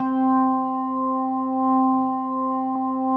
B3LESLIE C 5.wav